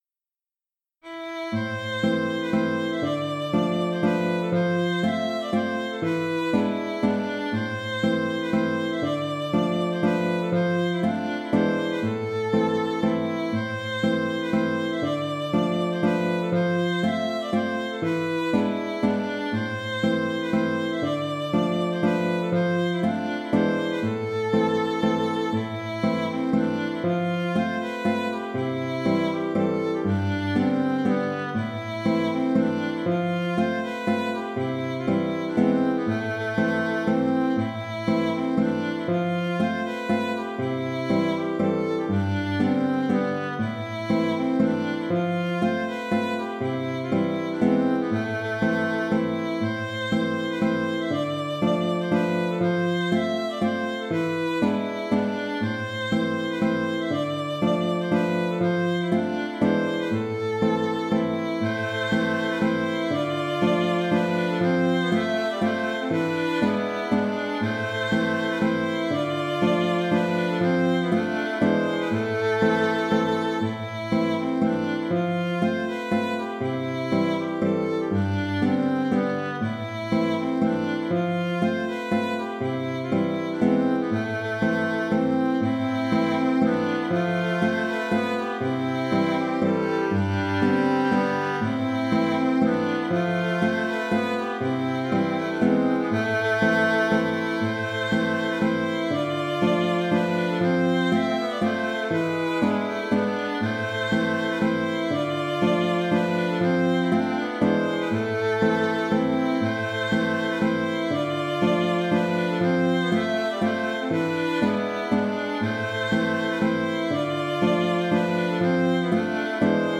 Le fichier mp3 fait entendre d’abord le morceau sans contrechant ; puis se rajoute le contrechant aux reprises ; et enfin thème et contrechant se réunissent sur tout le morceau. Dans ce dernier cas, le contrechant diffère un peu entre le A et sa reprise pour apporter un peu de variété.
Mazurka